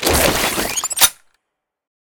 draw1.ogg